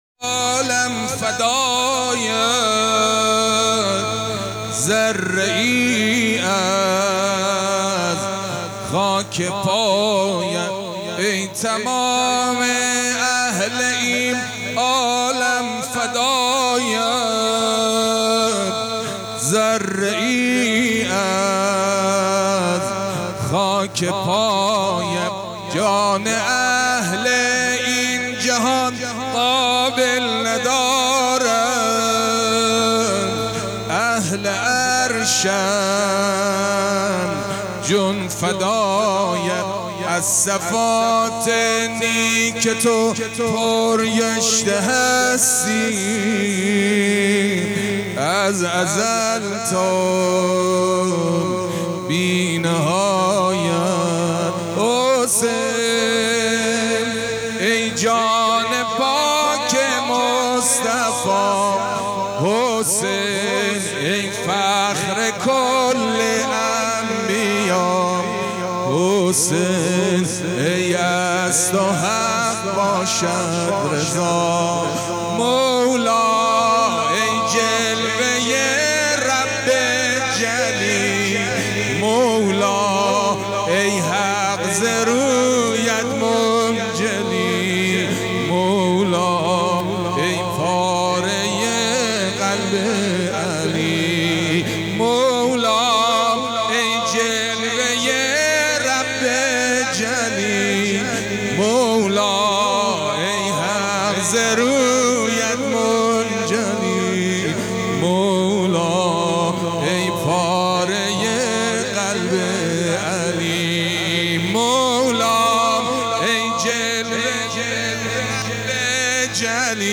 نوحه زنجیر زنی
هیئت عزاداری علی اصغری شهر